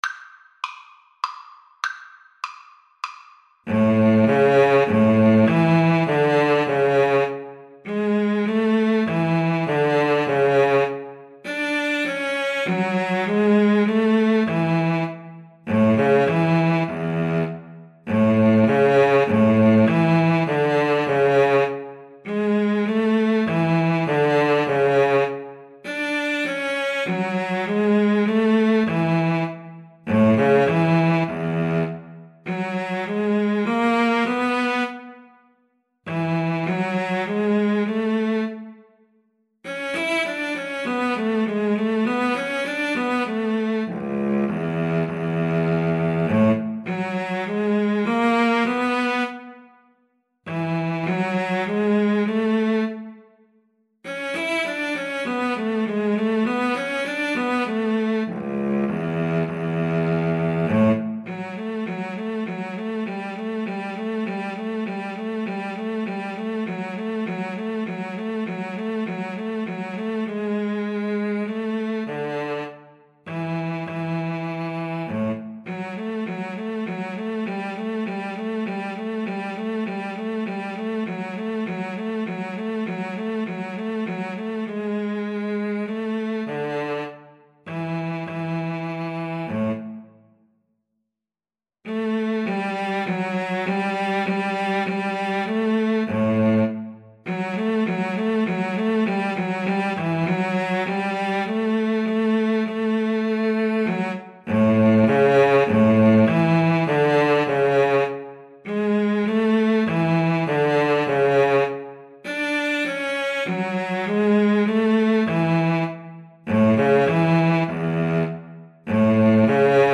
3/4 (View more 3/4 Music)
Viola-Cello Duet  (View more Easy Viola-Cello Duet Music)
Classical (View more Classical Viola-Cello Duet Music)